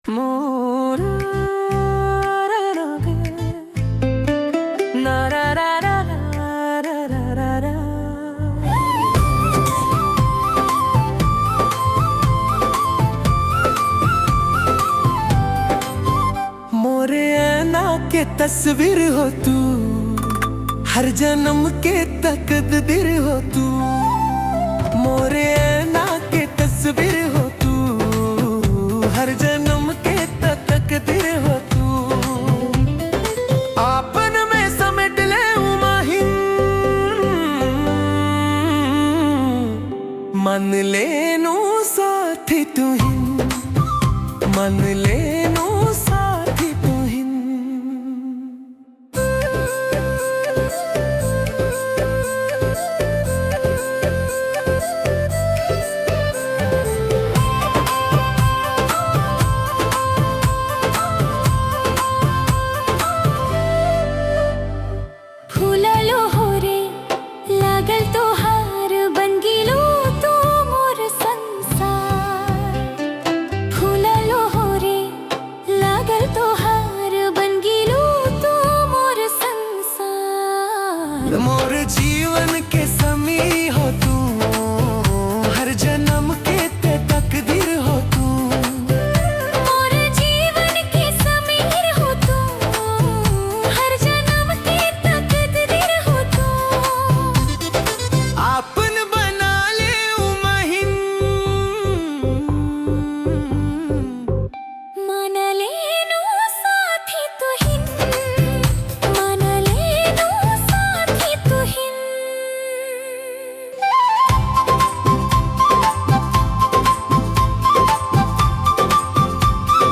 Tharu Item Dancing Song